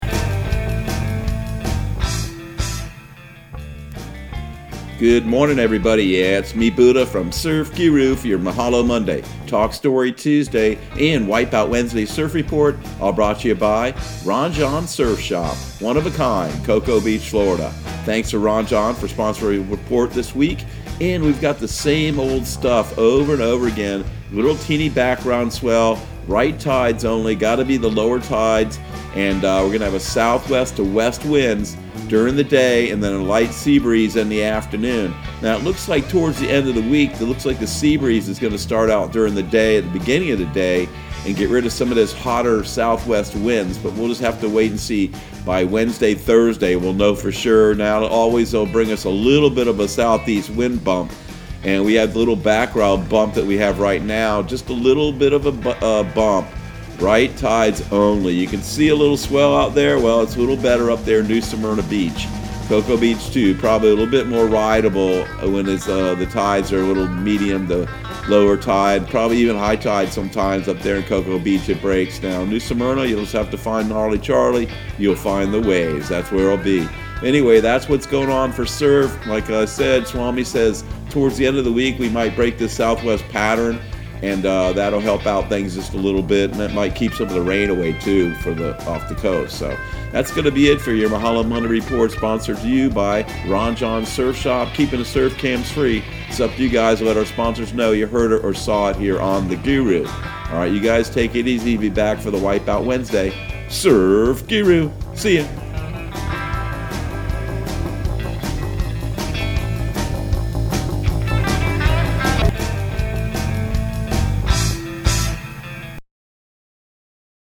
Surf Guru Surf Report and Forecast 07/13/2020 Audio surf report and surf forecast on July 13 for Central Florida and the Southeast.